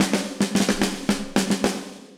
AM_MiliSnareC_110-02.wav